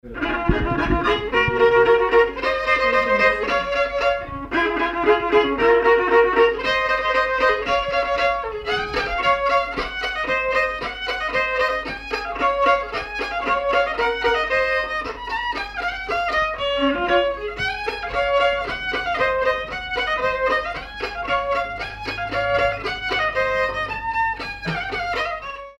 danse : polka
circonstance : bal, dancerie
Pièce musicale inédite